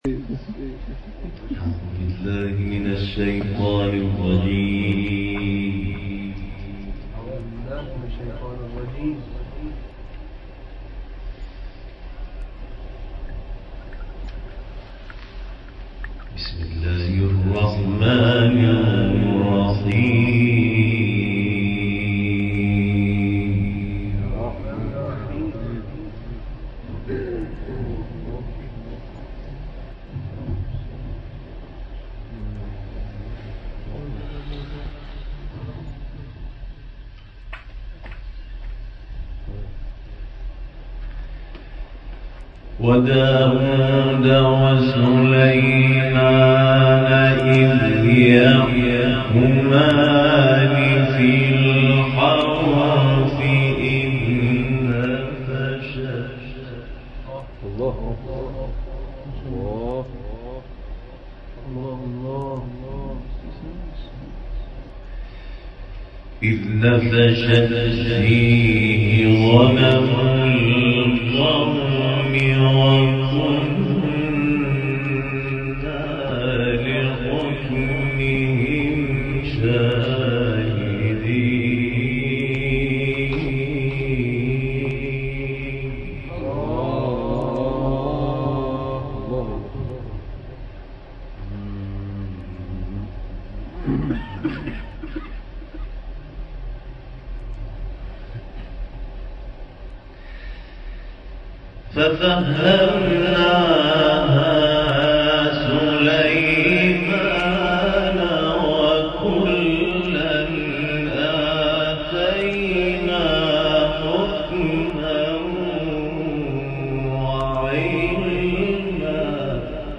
به گفته حاضران در این مجلس، این تلاوت یکی از شاهکارهای این قاری بین المللی است که در ادامه ارائه می‌شود.